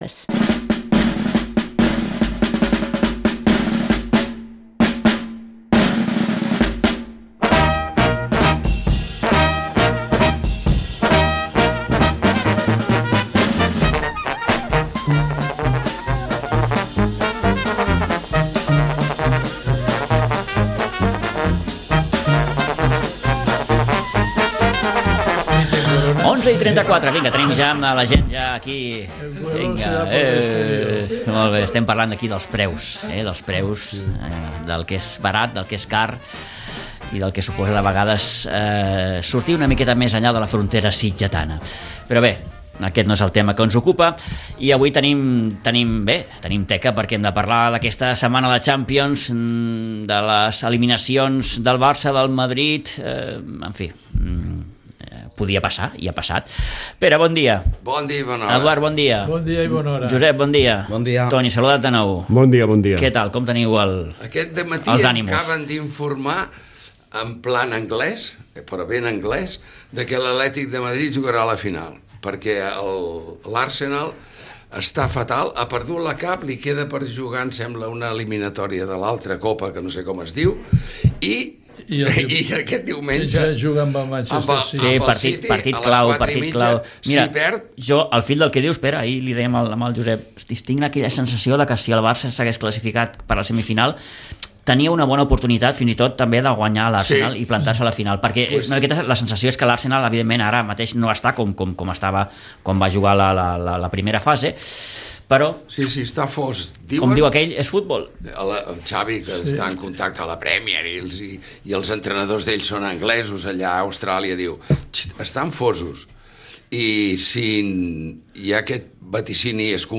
La tertúlia esportiva